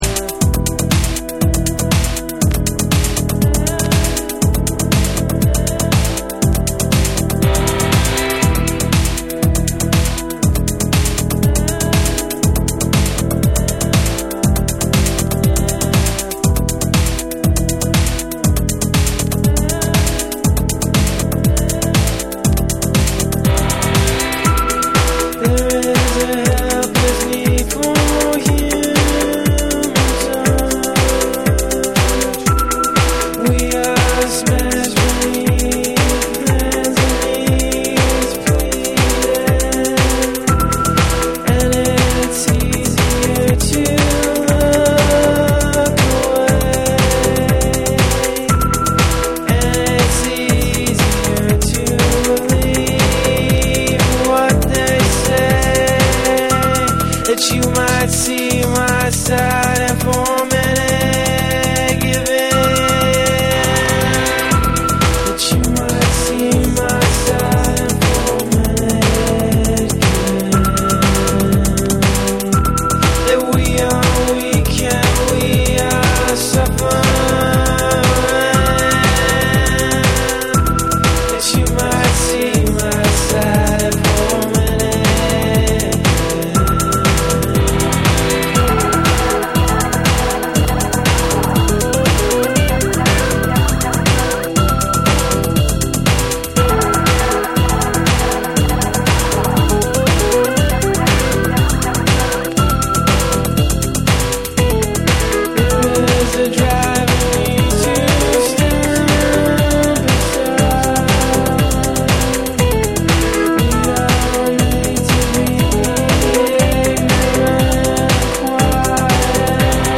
柔らかくも芯のあるボーカルが心に響く、メランコリックなメロディが印象的なダンス・ナンバー
BREAKBEATS